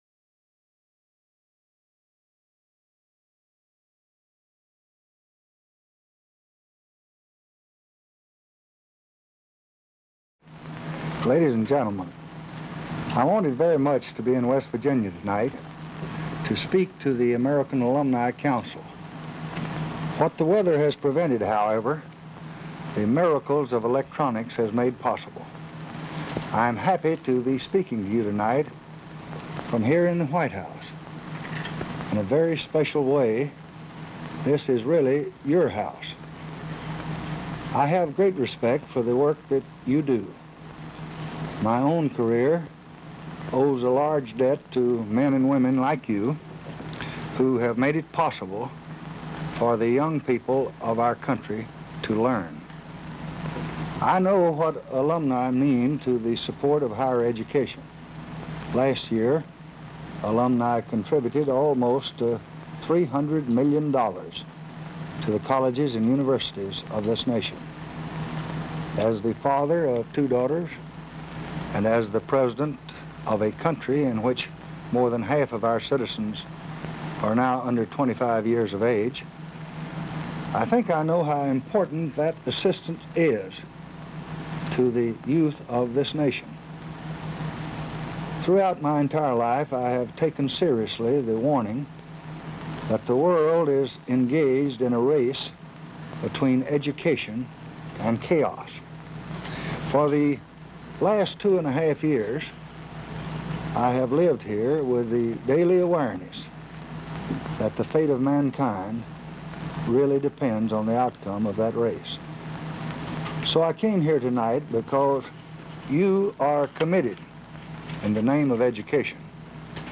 Presidential Speeches | Lyndon B. Johnson Presidency